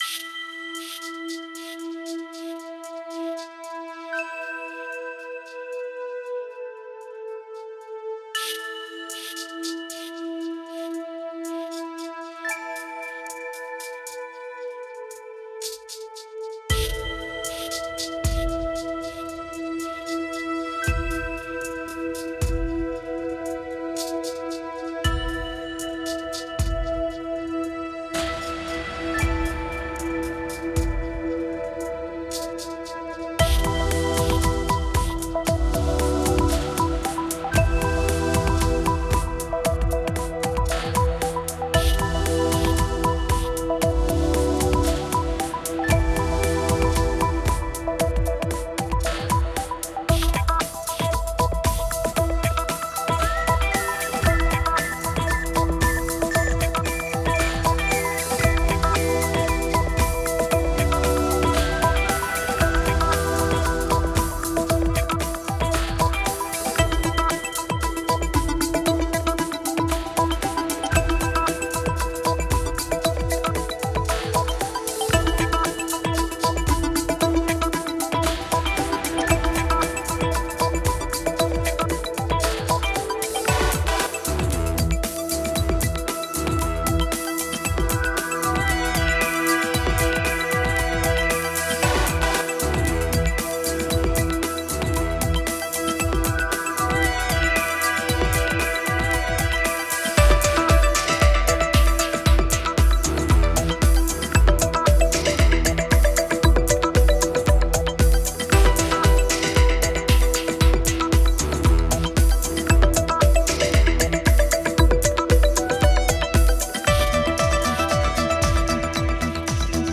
Genre: IDM, Electronic.